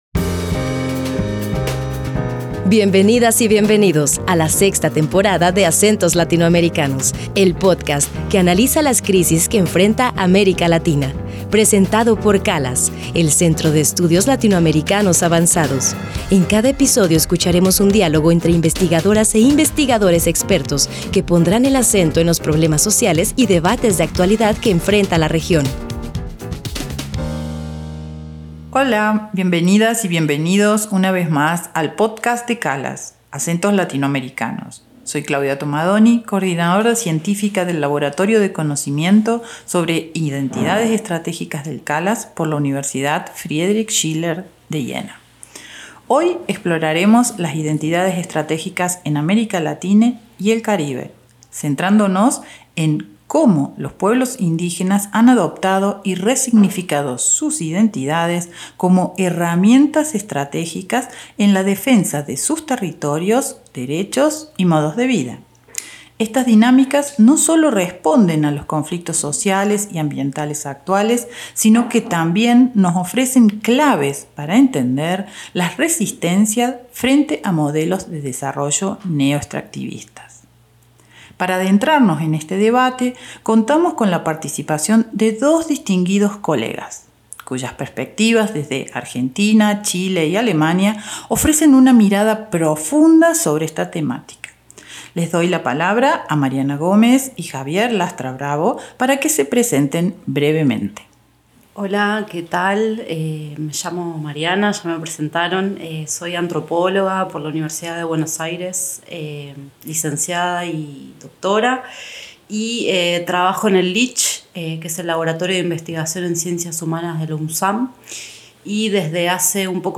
Además, escuchamos voces indígenas que cuestionan el patriarcado y los esencialismos culturales, disputando espacios académicos y políticos para redefinir su propia representación.